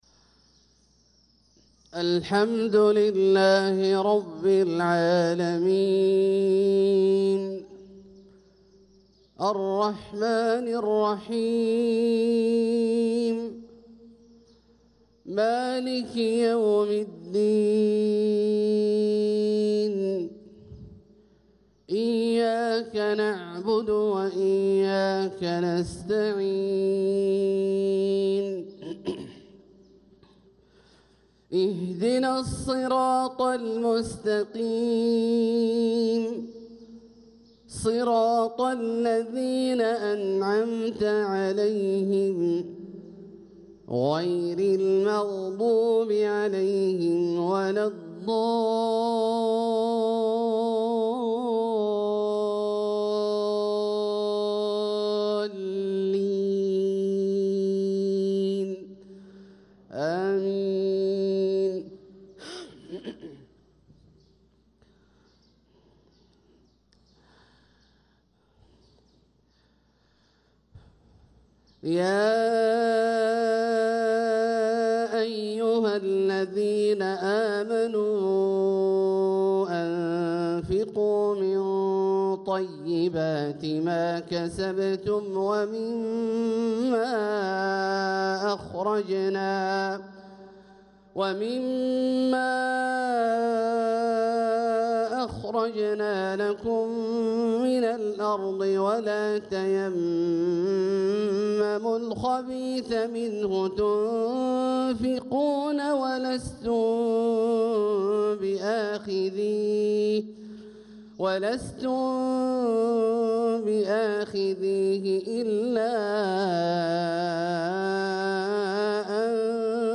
صلاة الفجر للقارئ عبدالله الجهني 5 جمادي الأول 1446 هـ
تِلَاوَات الْحَرَمَيْن .